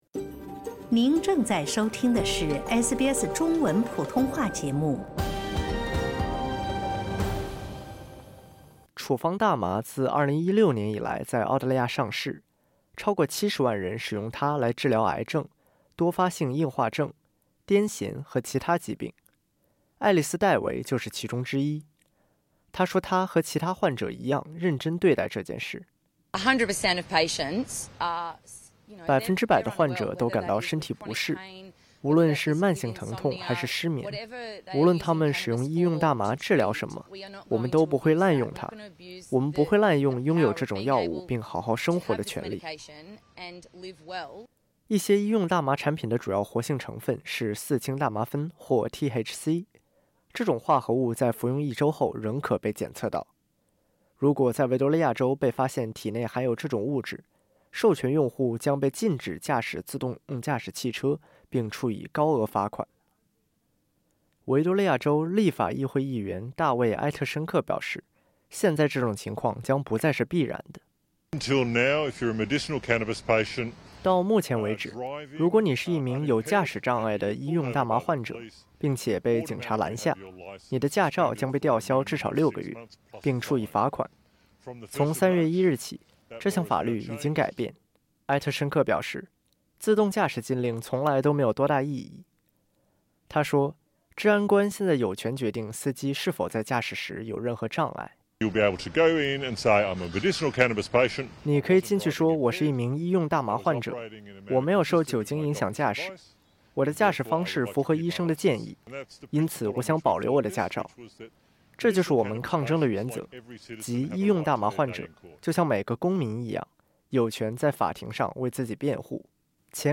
根据维多利亚州即将生效的新法律，在维多利亚州使用医用大麻的司机将不再受到自动驾照禁令的约束。从 3 月 1 日起，此类驾驶者将有机会在法庭上辩称他们在驾驶时没有受到药物影响（点击音频，收听完整报道）。